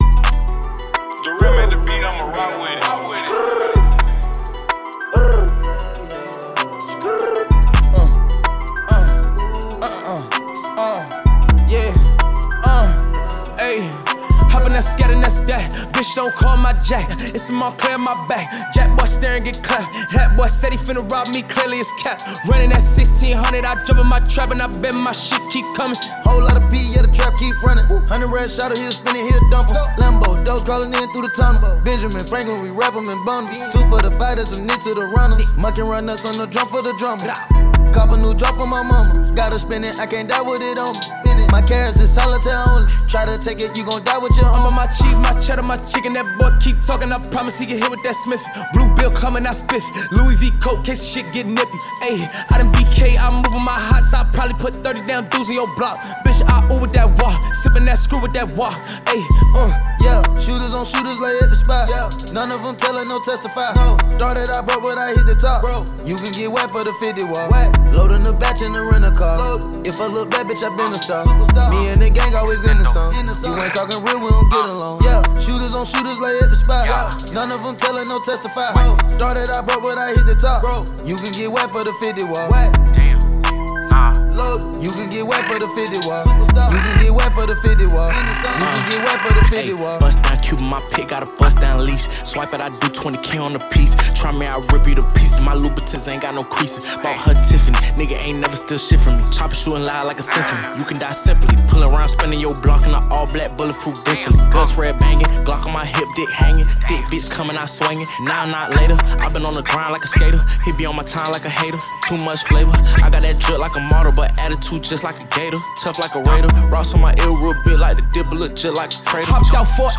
Canadian rapper and singer
American rappers